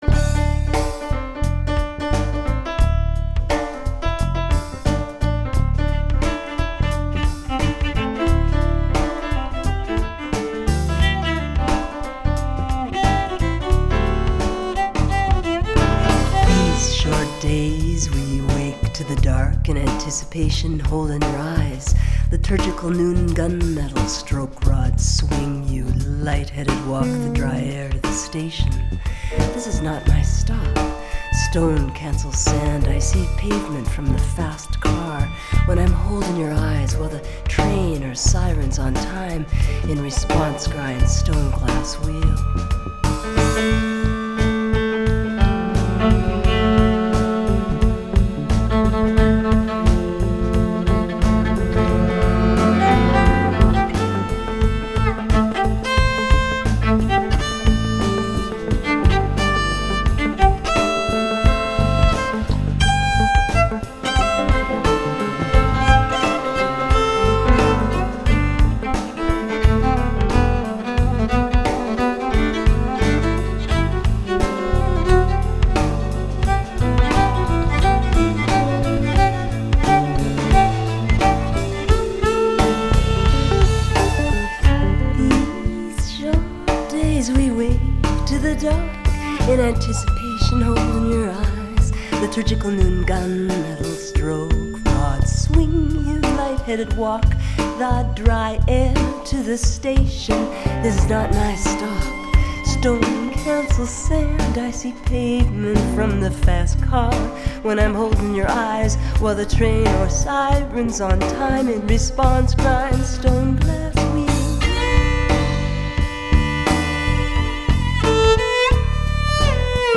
bass
alto and baritone sax
guitar
tenor sax
viola
drums
piano